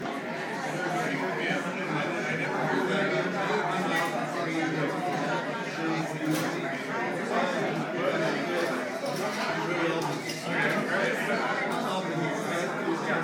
So many voices in so many conversations at once. It is a din of activity, not a den of iniquity as many of you thought.